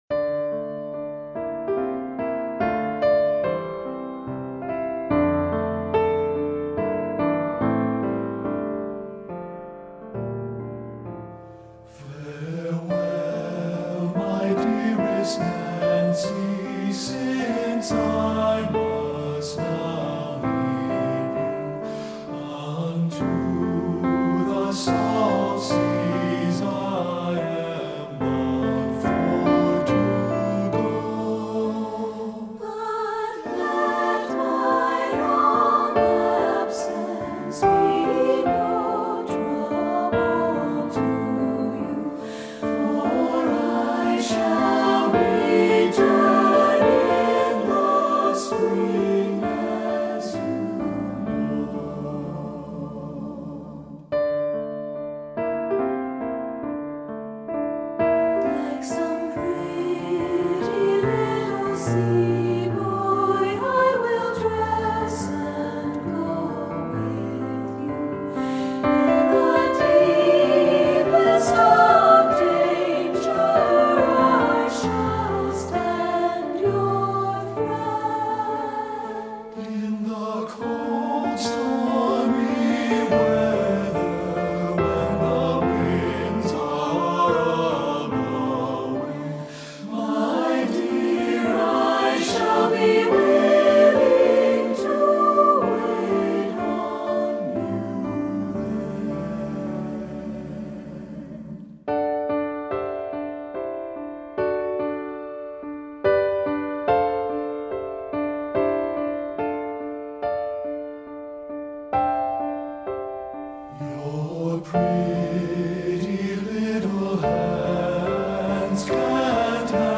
Composer: Irish Folk Song
Voicing: SATB